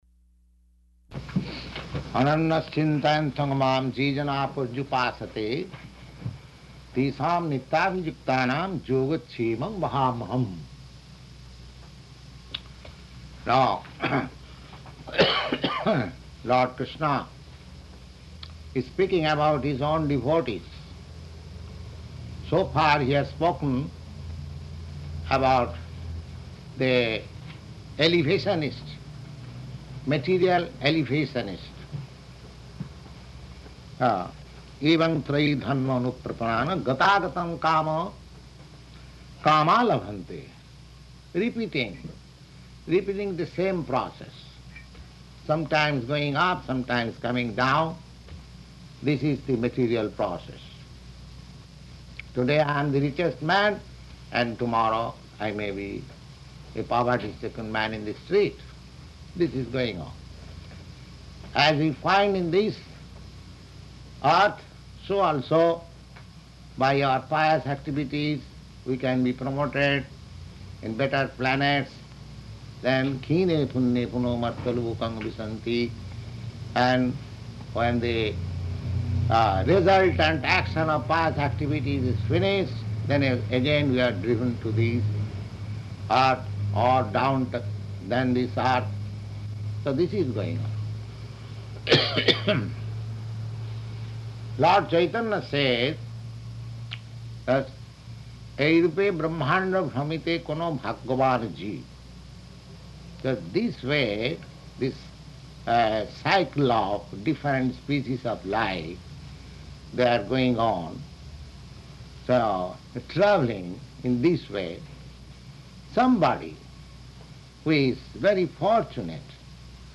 Type: Bhagavad-gita
Location: New York
661209BG-NEW_YORK.mp3